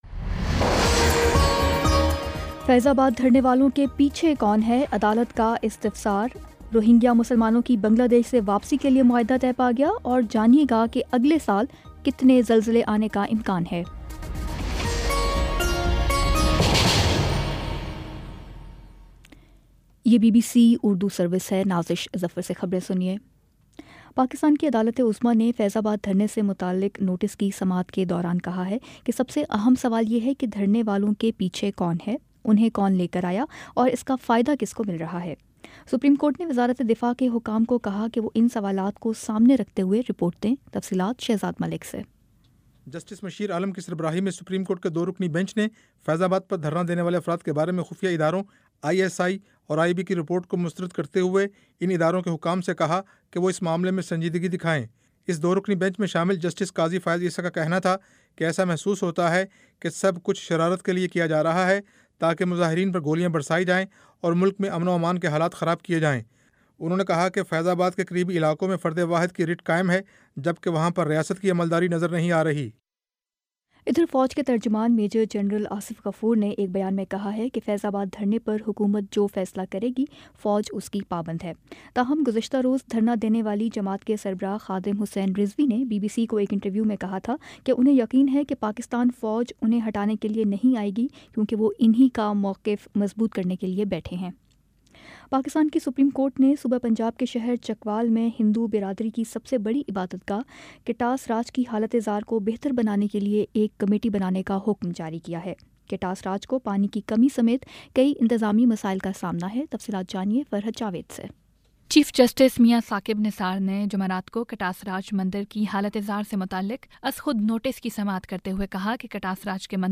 نومبر 23 : شام پانچ بجے کا نیوز بُلیٹن